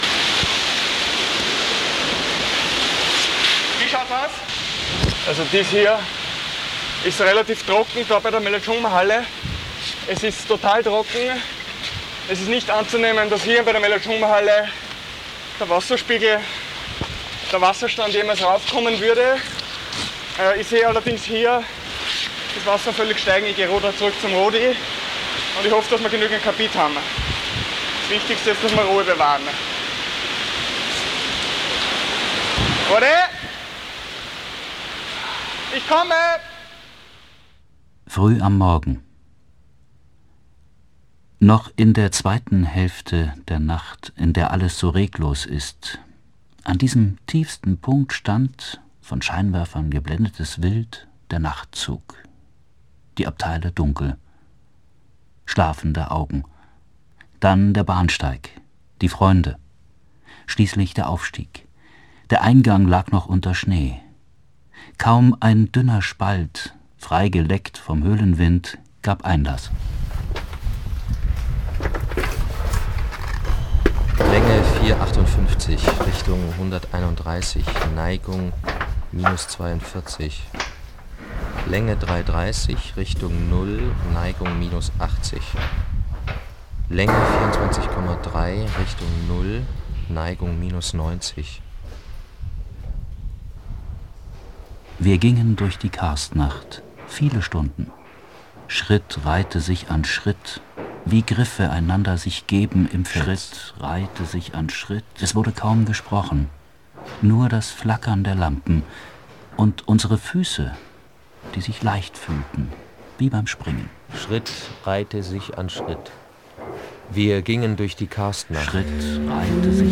Das Labyrinth unter der Erde wird hörbar im Labyrinth aus Tönen und Worten.